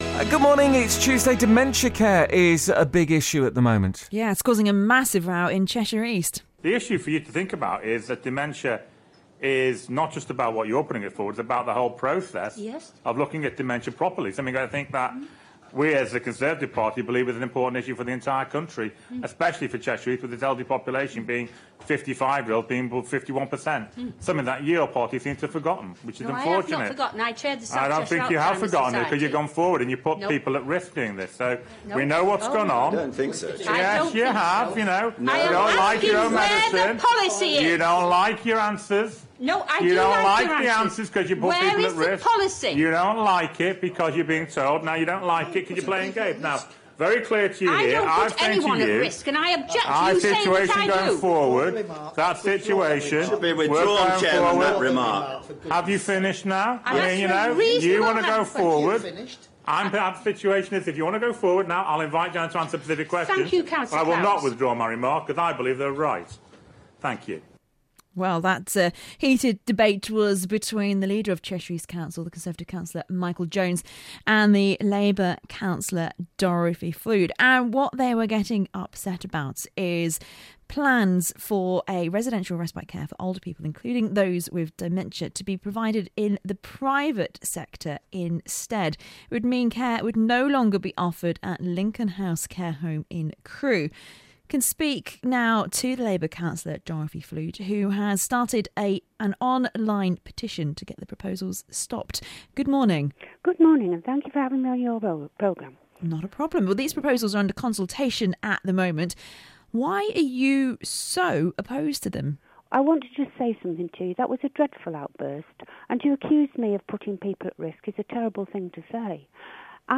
Hear the row between Labour's Dorothy Flude and Cheshire East leader Michael Jones on the plans to remove respite care from Lincoln House in Crewe, and an interview with Cllr Flude. Audio of council meeting from Cheshire East Council.